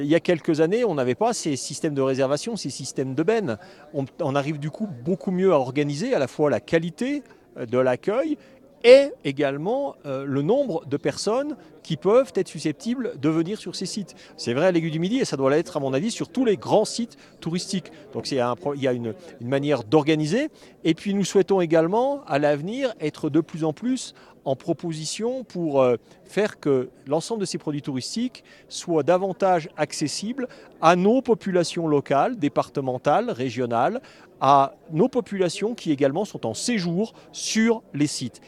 ITG Éric Fournier 2 – 70 ans Aiguille du Midi